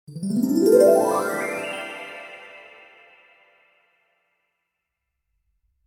Download Free Magic Sound Effects
Download Magic sound effect for free.